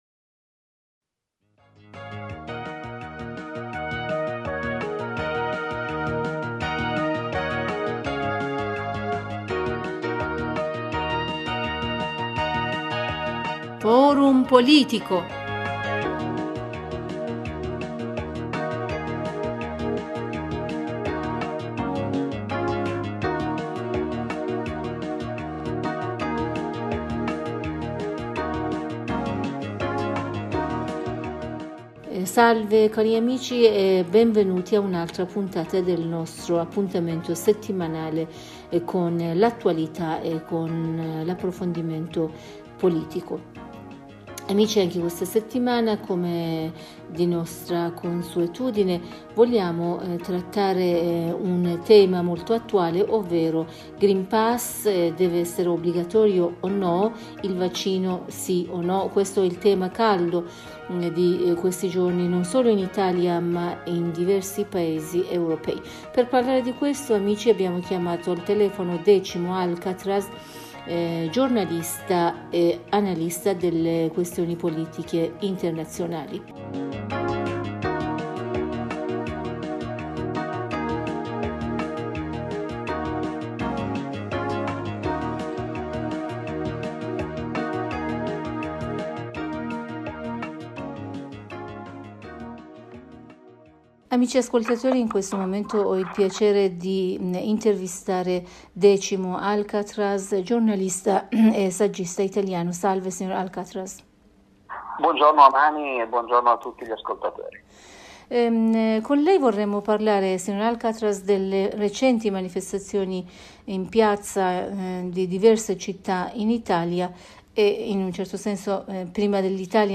in un collegamento telefonico